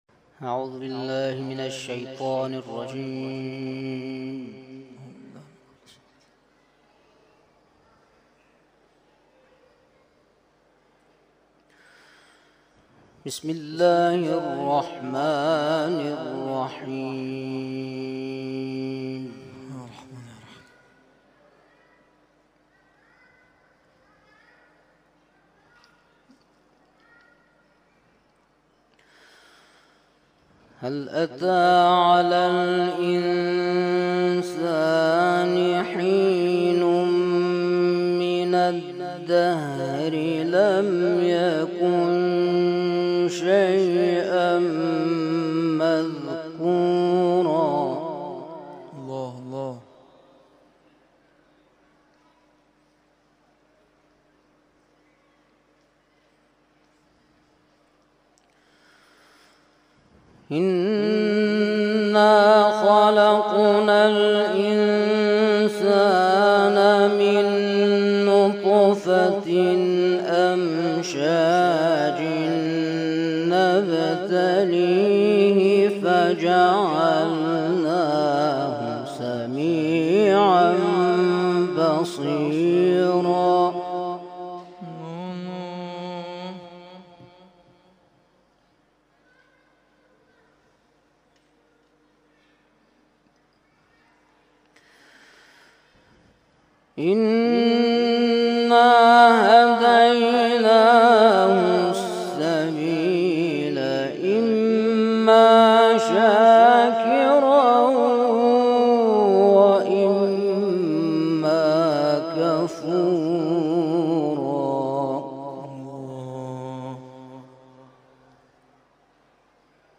تلاوت‌های محفل قرآنی سوم شهریور آستان عبدالعظیم(ع)